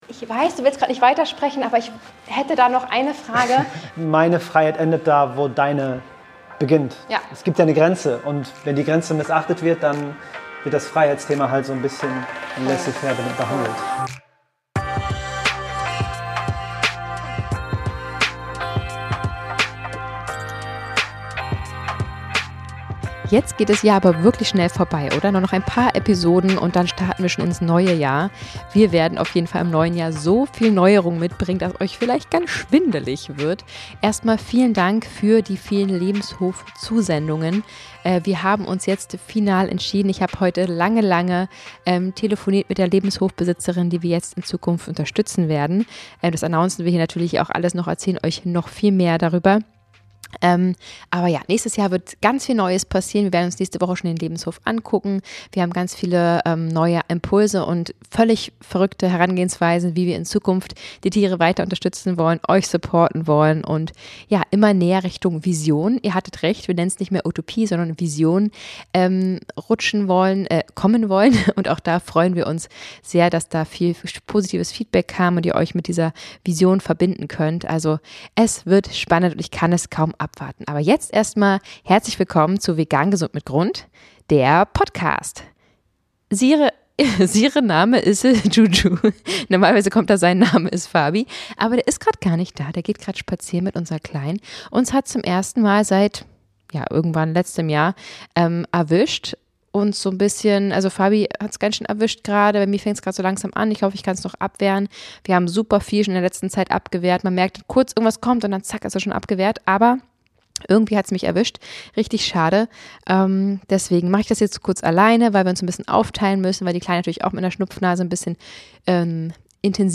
Live Podcast über vegane Klischees - Veggieworld 2024 ~ Vegan Superheroes Podcast